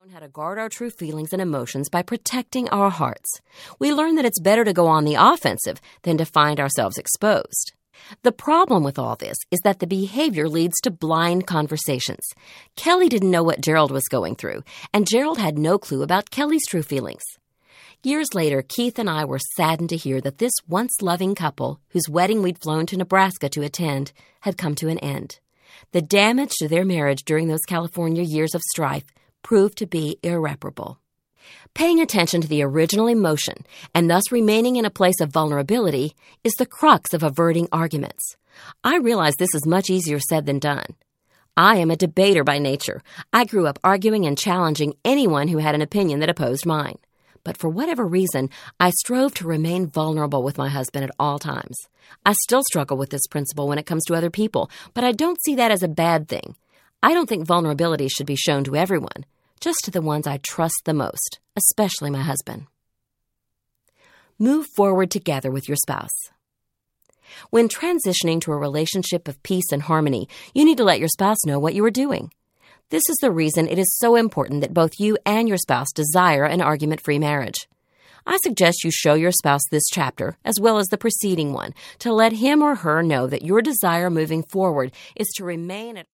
Narrator